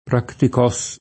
pratico [pr#tiko] agg.; pl. m. ‑ci — dell’uso ant., soprattutto fra ’400 e ’600, la var. dòtta prattico [pr#ttiko], più vicina al lat. practicus [pr#ktikuS] e al gr. πρακτικός / praktikós [